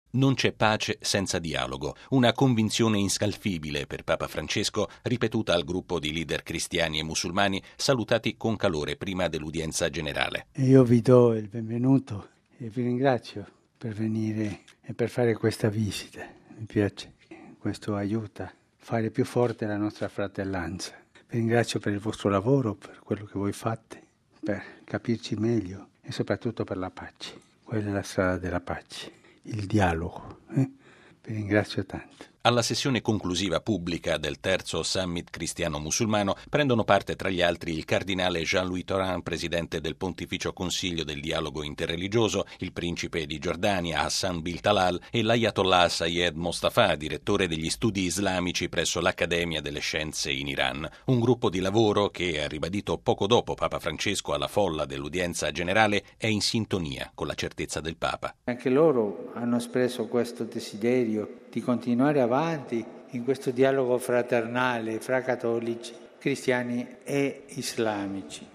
Poco prima dell’udienza generale, nell’Auletta dell’Aula Paolo VI Papa Francesco ha incontrato per un breve saluto una trentina di partecipanti al terzo Summit “of Christian and Muslim Leaders”.